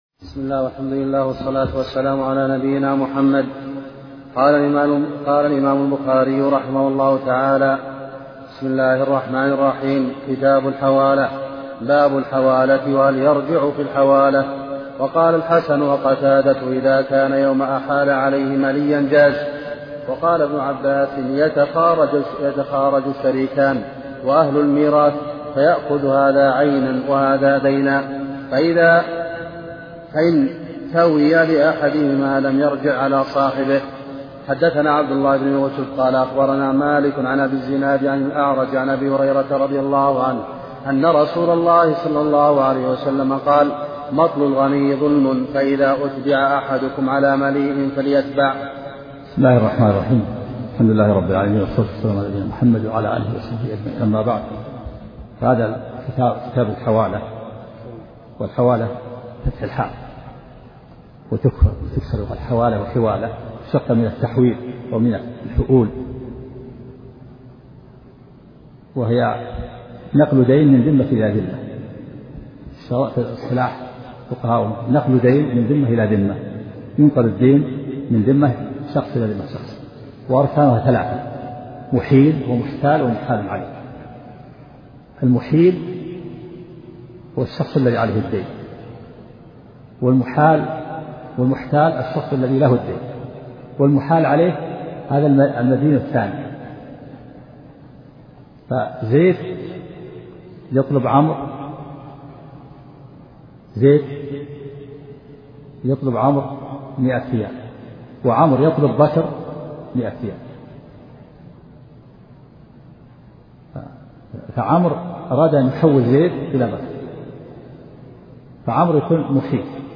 محاضرة صوتية نافعة، وفيها شرح الشيخ عبد العزيز بن عبد ا